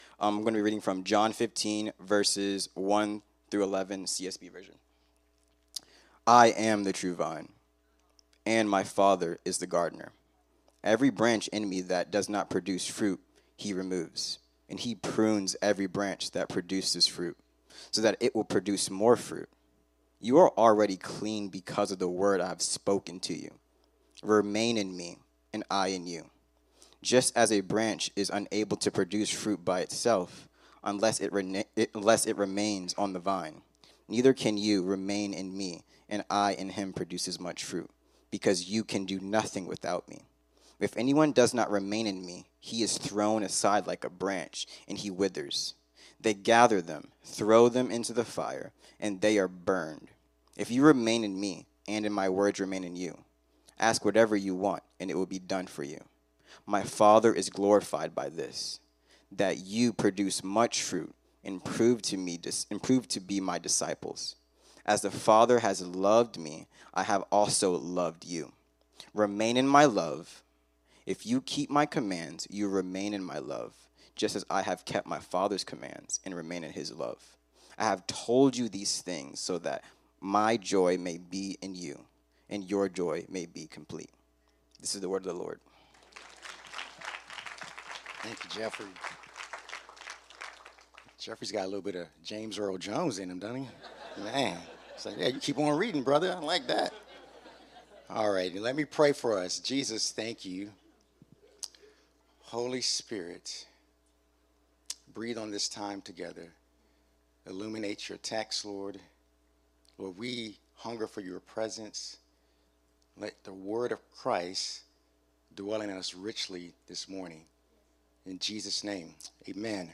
Increasing the Good Life Service Type: Sunday 10am « Increasing the Good Life